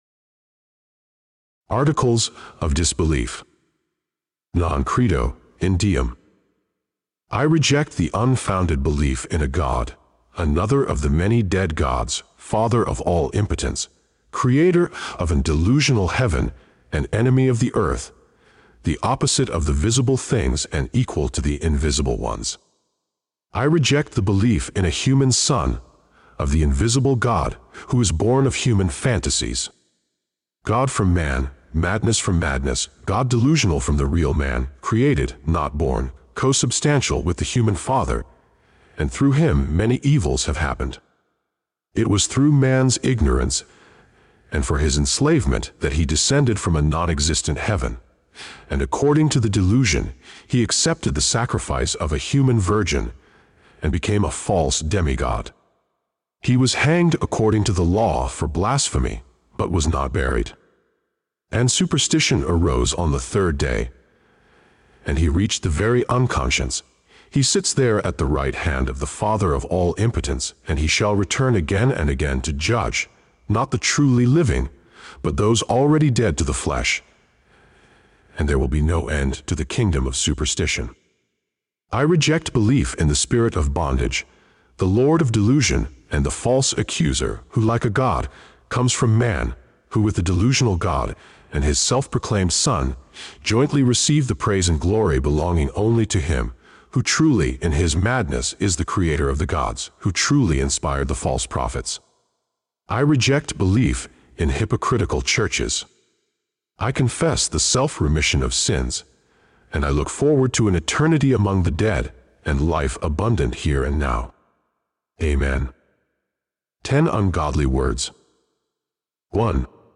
The Satanic Kerygma Audiobook